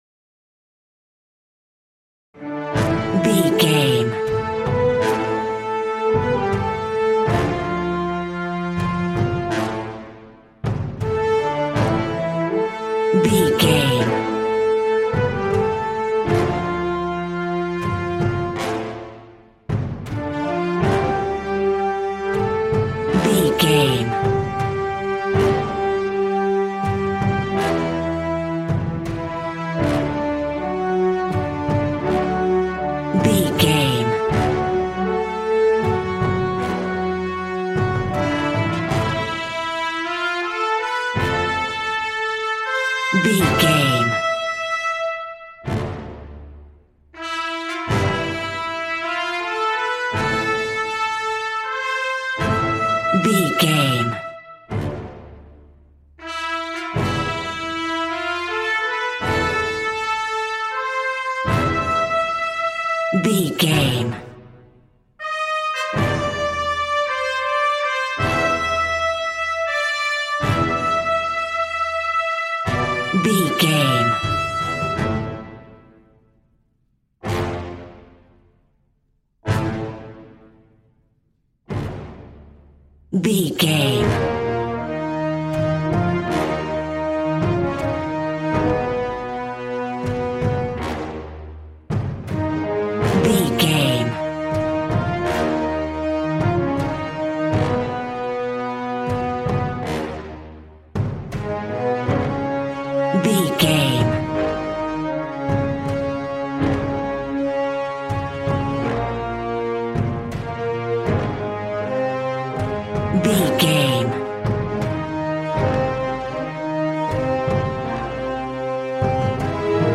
Valiant and Triumphant music for Knights and Vikings.
Regal and romantic, a classy piece of classical music.
Aeolian/Minor
brass
strings
violin
regal